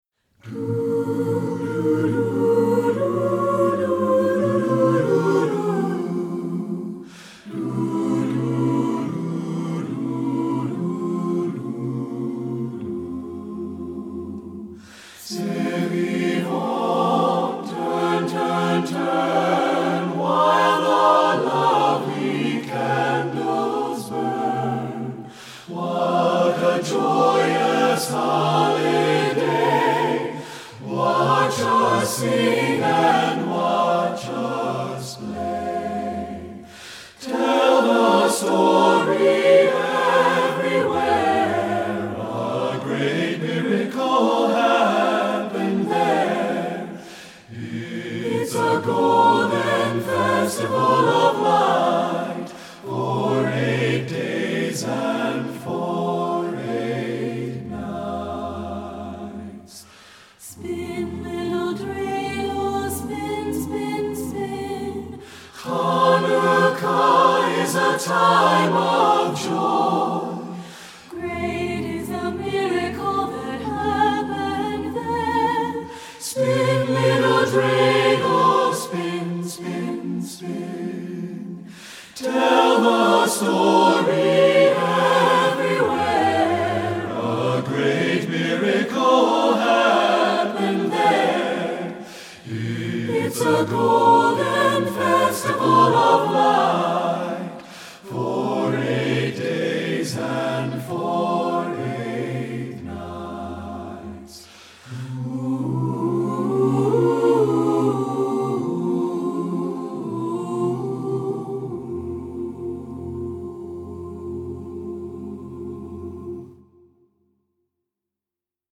Composer: Jewish Folk Song
Voicing: SATB a cappella